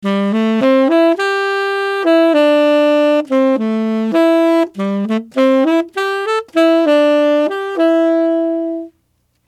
[影视音效][动听的吹萨克斯的音效][剪辑素材][音频素材下载]-8M资料网